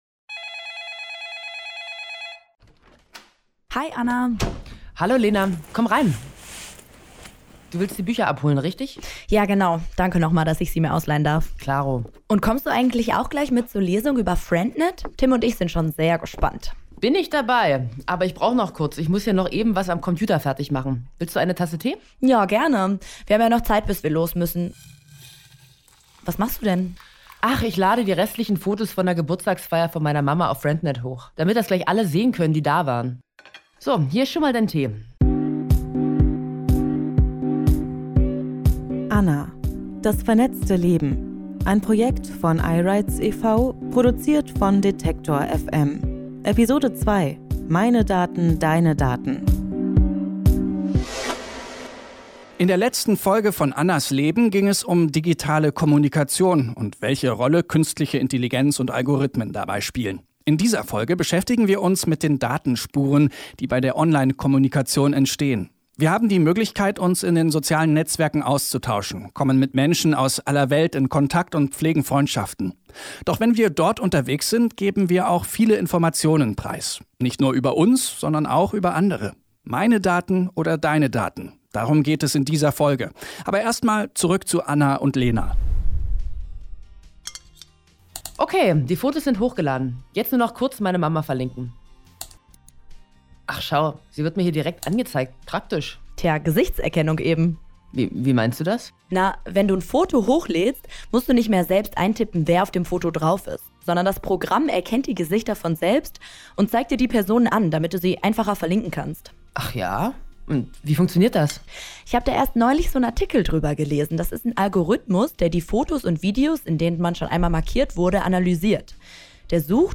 Sprecherinnen und Sprecher: